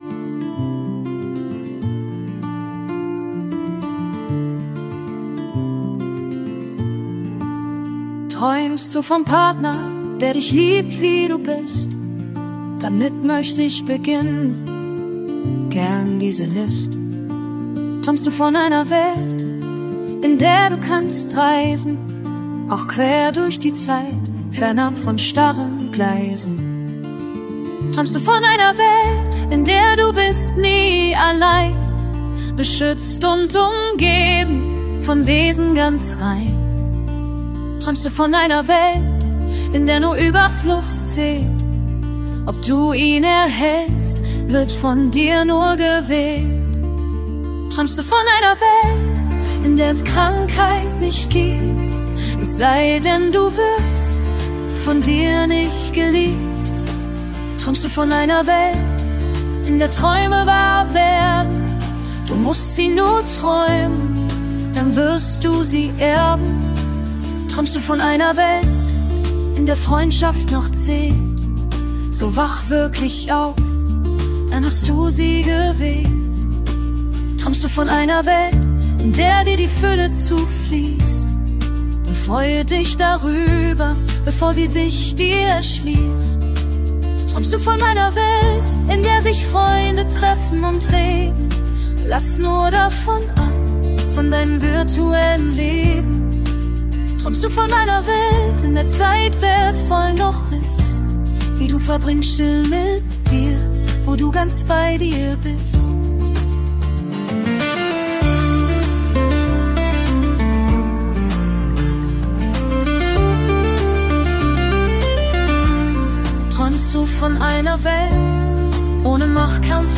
Folk Song